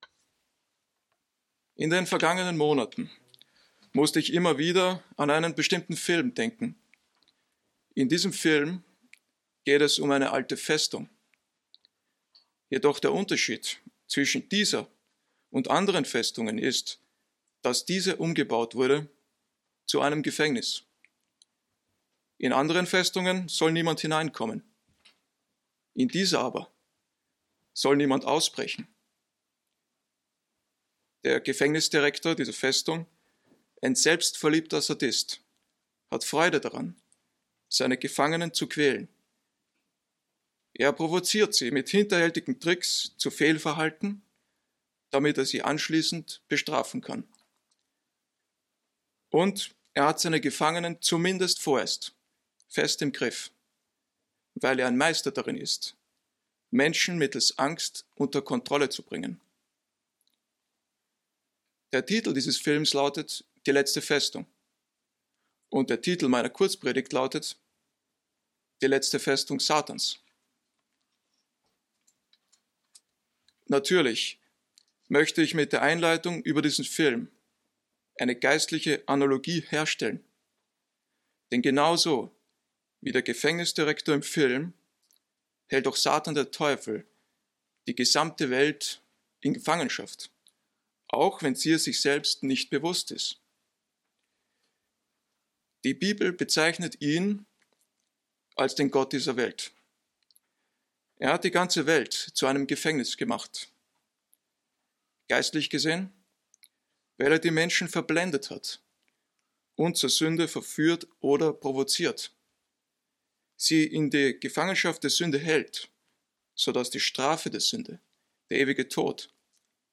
Der Titel der Kurzpredigt ist von einen Hollywood Film abgeleitet. Die im Film dargestellten Zustände einer Gefängnis-Festung werden mit der Situation verglichen, die Satan, der Teufel, über die Welt gebracht hat. Auch werden in diesem Zusammenhang künftige schreckliche prophetische Ereignisse angesprochen sowie tröstende und hoffnungsvolle Aussagen der Bibel.